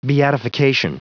Prononciation du mot beatification en anglais (fichier audio)
Prononciation du mot : beatification
beatification.wav